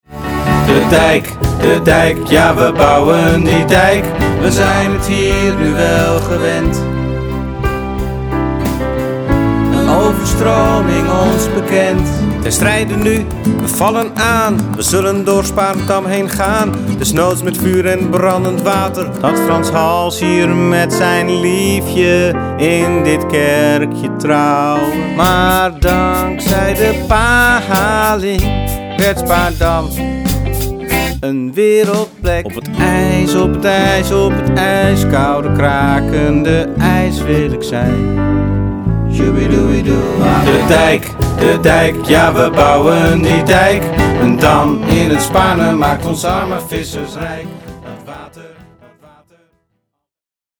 Dit is een compilatie van alle nummers van de musical.
Hierin wordt de geschiedenis van Spaarndam in moderne liedjes beschreven.